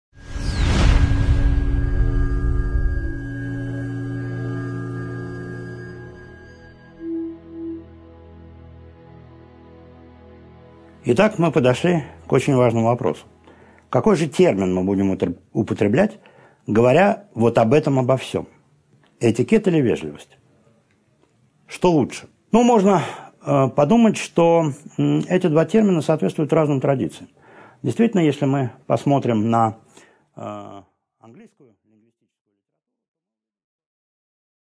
Аудиокнига 1.4 Этикет или вежливость?